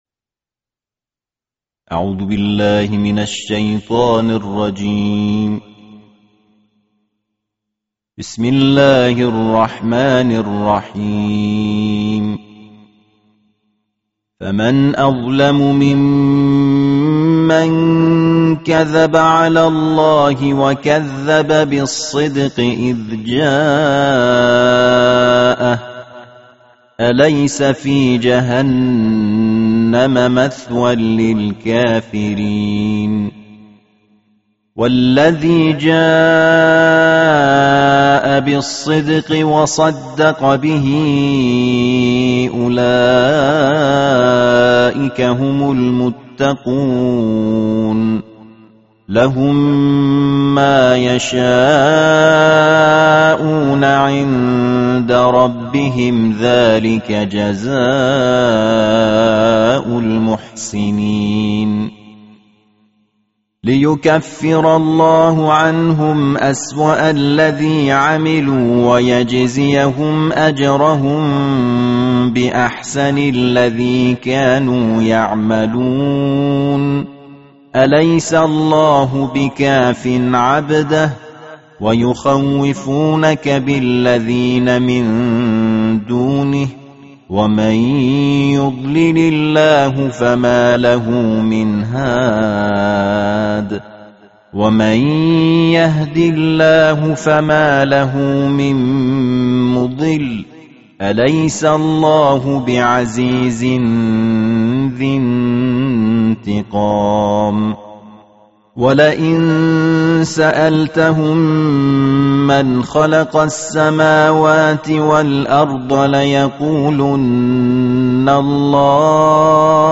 тартиль 24